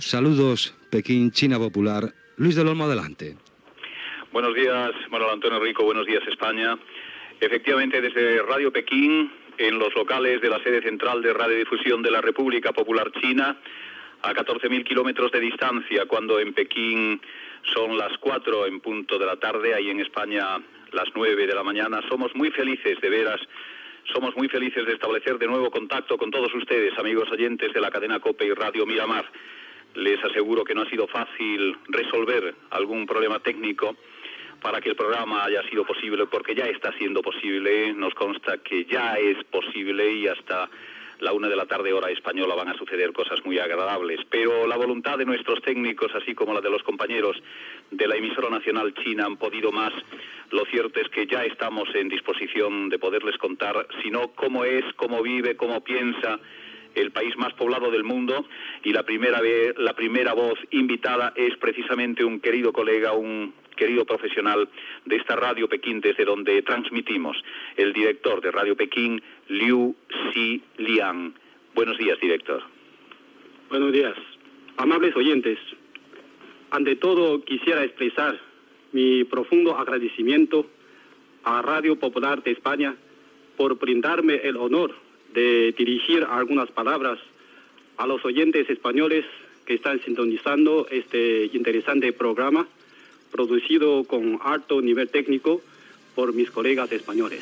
Programa emès des de Ràdio Pekín a la Xina.
Info-entreteniment